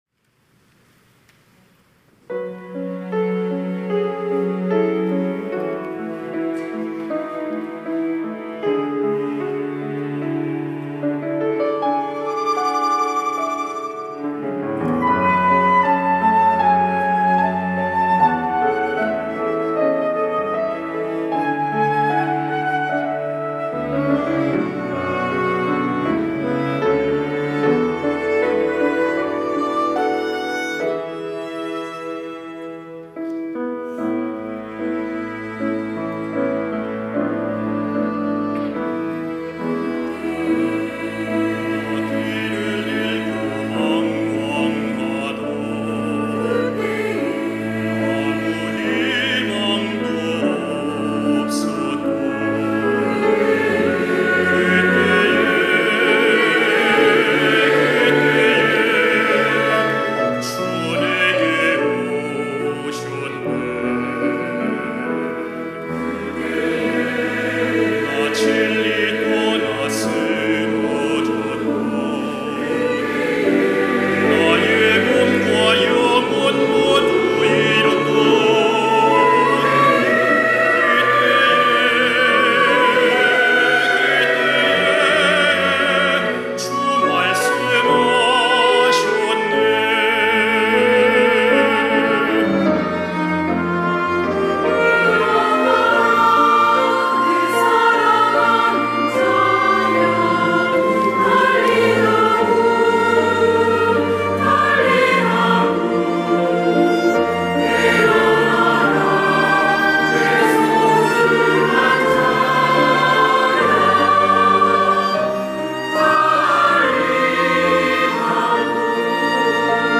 호산나(주일3부) - 달리다굼
찬양대